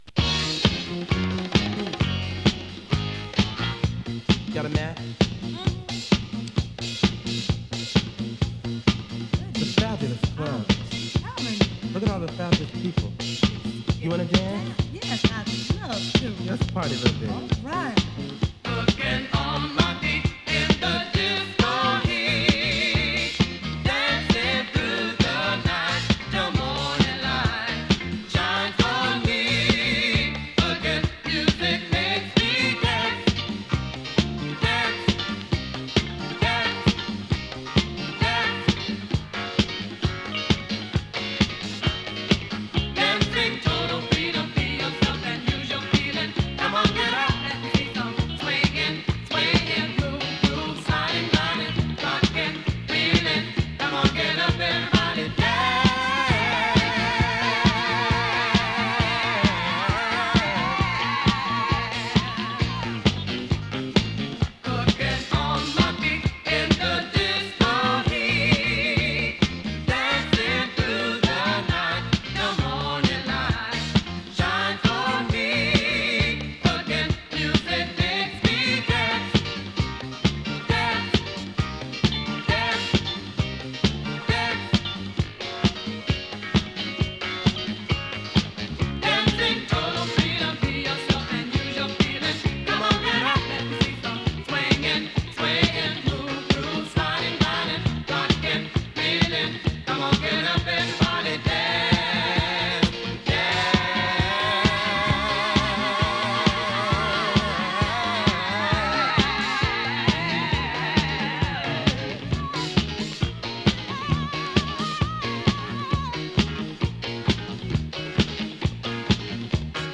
ディスコ